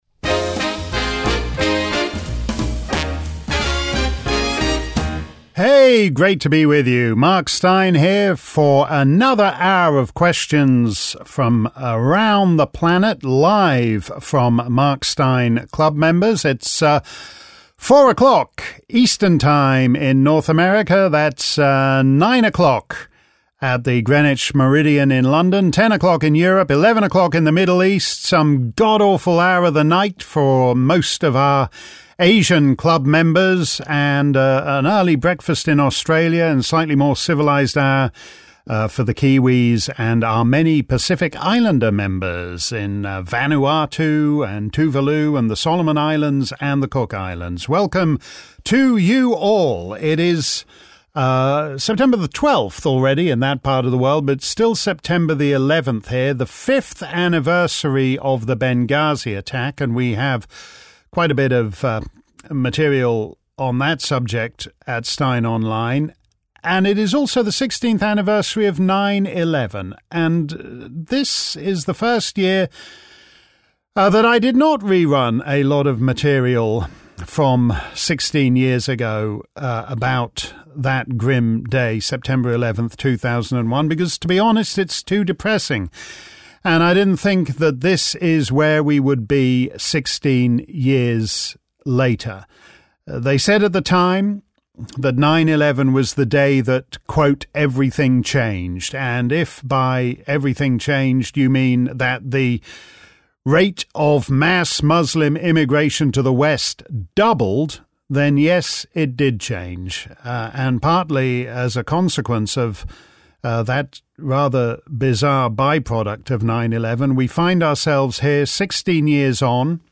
If you missed our livestream Clubland Q&A earlier today, here's the action replay.